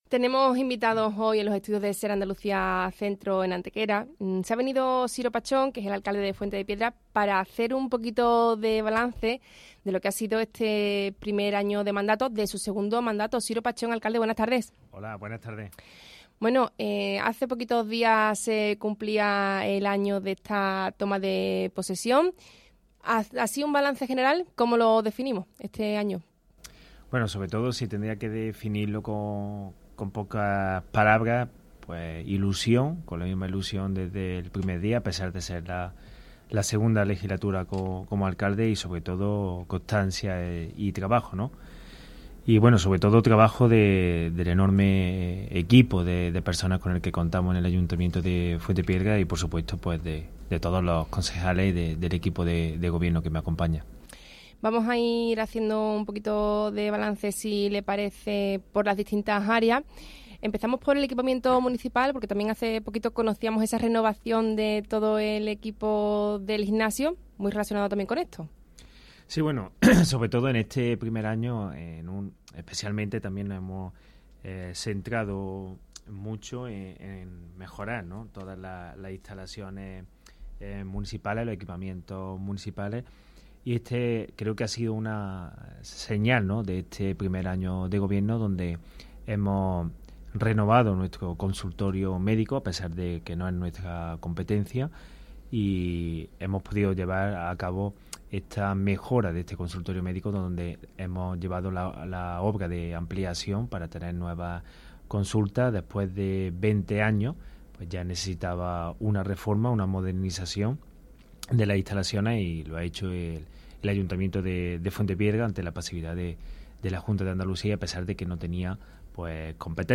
Entrevista Siro Pachón. Balance 1º año de mandato.